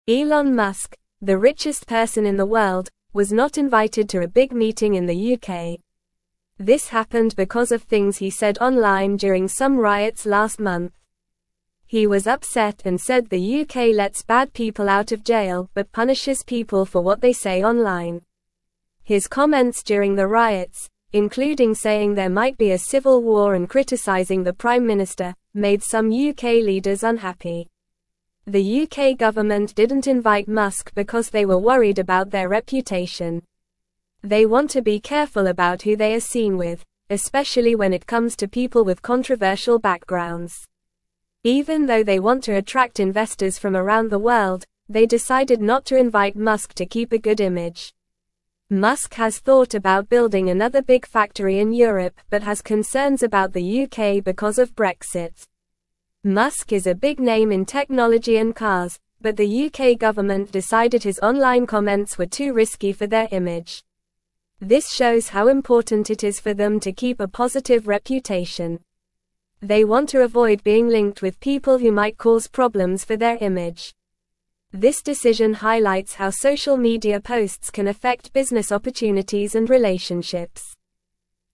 Normal
English-Newsroom-Lower-Intermediate-NORMAL-Reading-Elon-Musk-not-invited-to-UK-meeting-upset.mp3